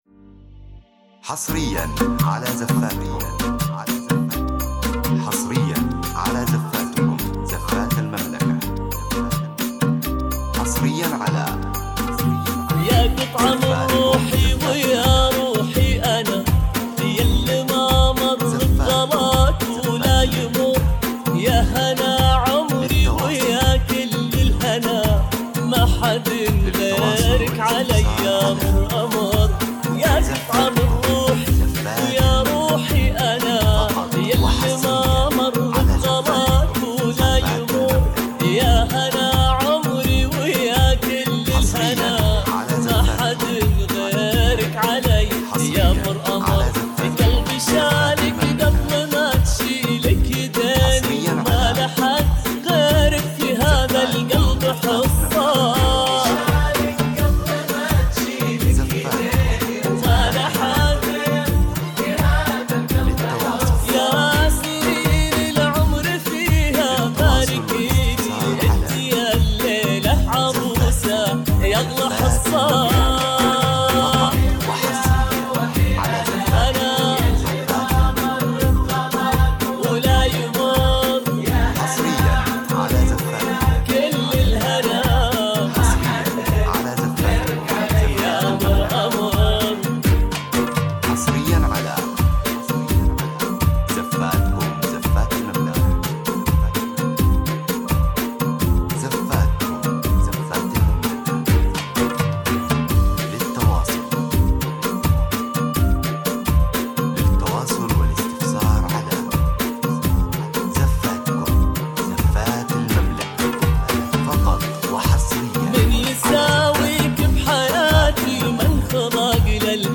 • الزفات الإسلامية بدون موسيقى